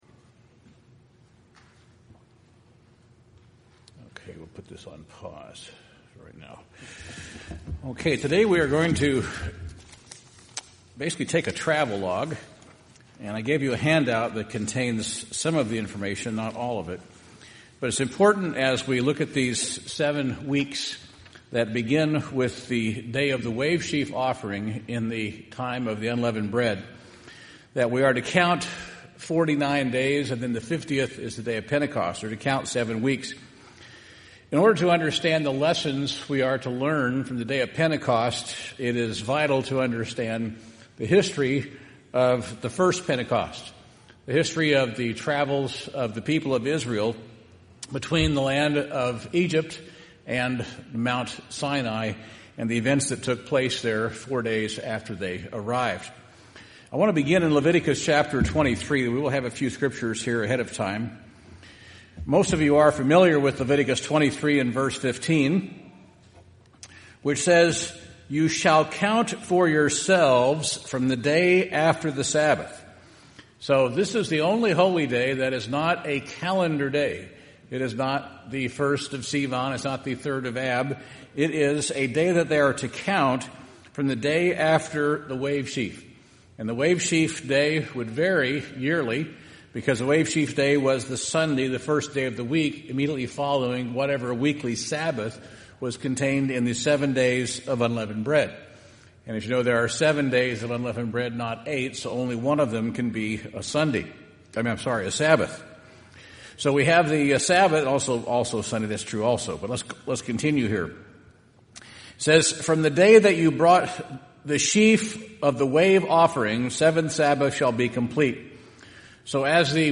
This sermon discusses the events leading up to Pentecost at Mount Sinai. It also reveals New Testament references to weeks.
Given in Portland, OR